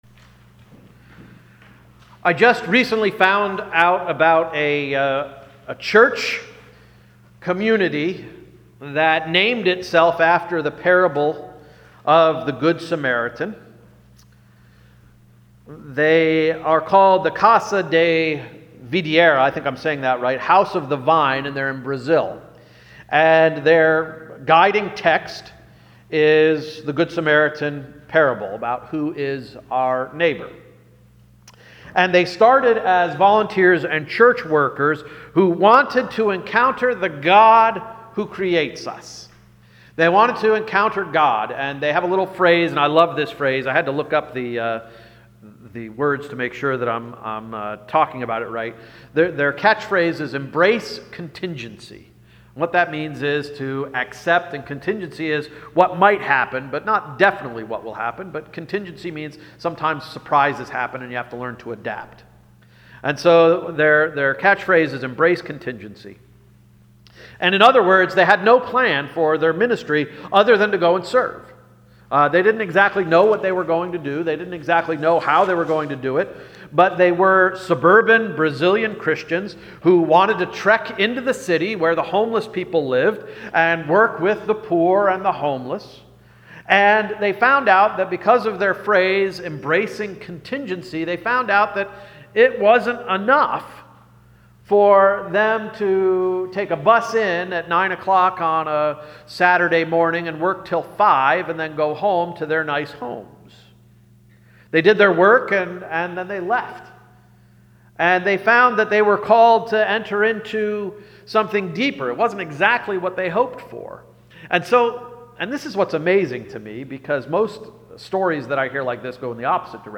March 12 Sermon — Q & A Session
q-a-session.mp3